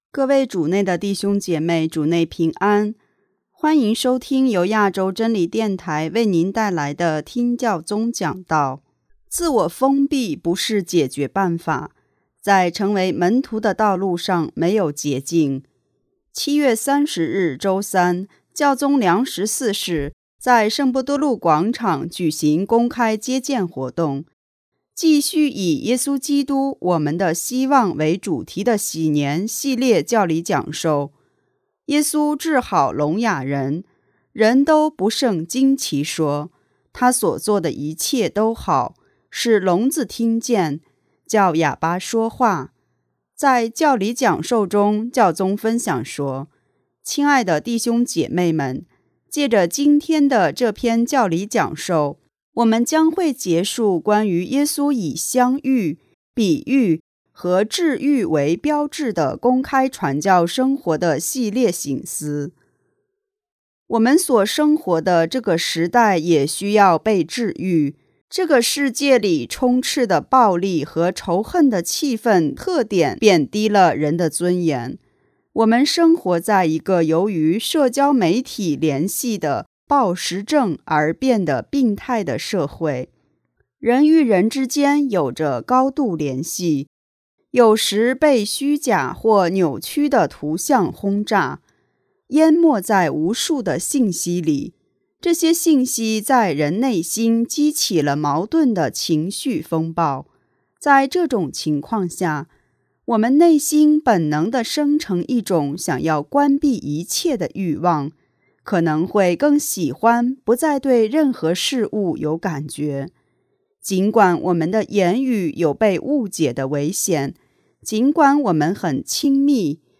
7月30日周三，教宗良十四世在圣伯多禄广场举行公开接见活动，继续以“耶稣基督、我们的希望”为主题的禧年系列教理讲授，“耶稣治好聋哑人”。“